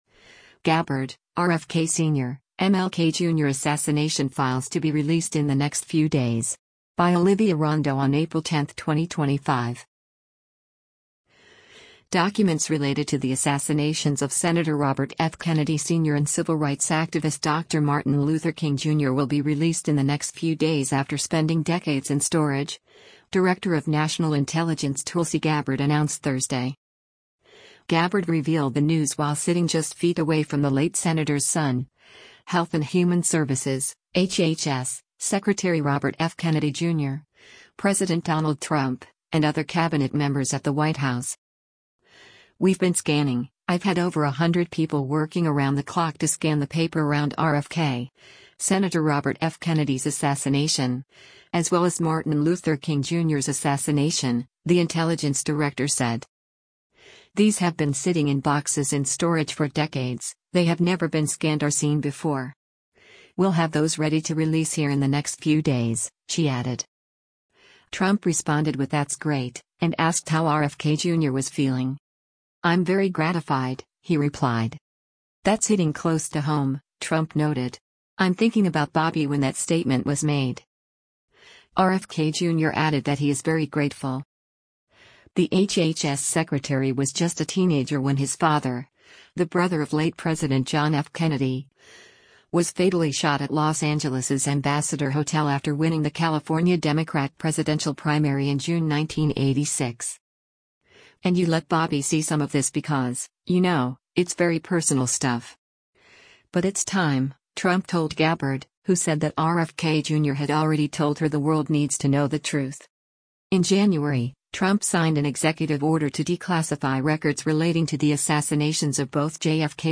Gabbard revealed the news while sitting just feet away from the late senator’s son, Health and Human Services (HHS) Sec. Robert F. Kennedy Jr., President Donald Trump, and other cabinet members at the White House: